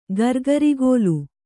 ♪ gargarigōlu